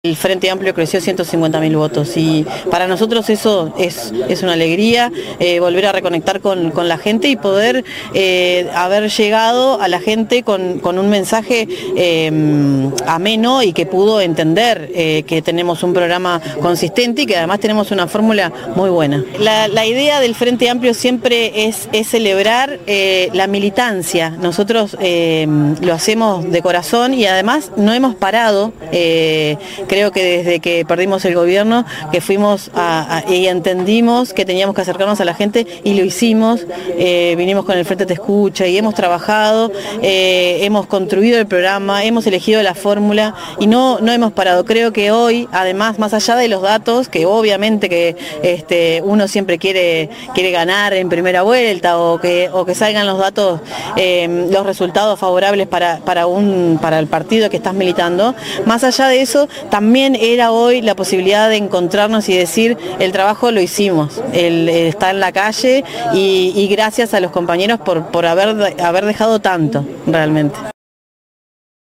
Sin tener aun los datos de Colonia al momento de la entrevista